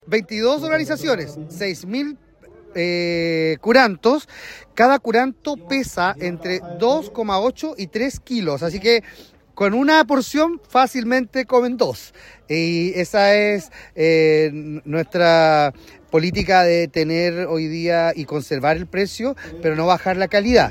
Por su parte, el alcalde de Calbuco, Marco Silva, destacó la organización del evento y la calidad de los platos ofrecidos al público.